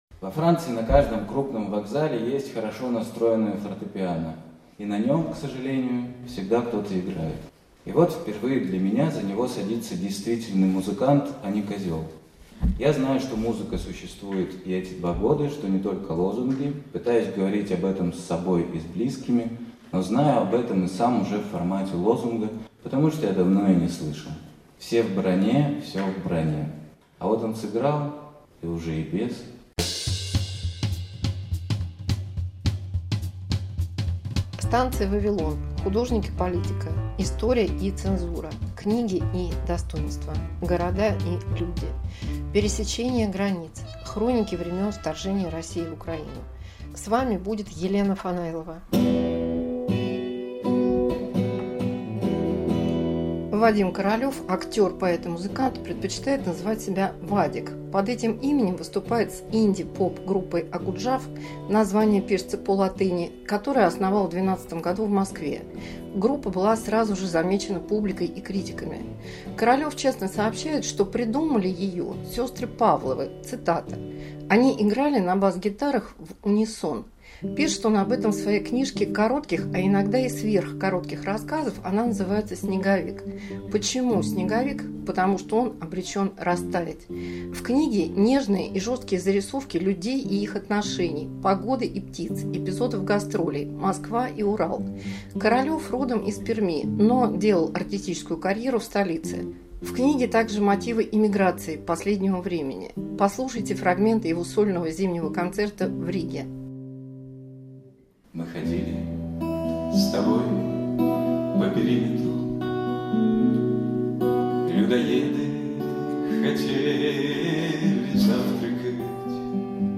Поэт и журналист Елена Фанайлова говорит со своими гостями о духе времени, о том, как искусство связано с экономикой и политикой.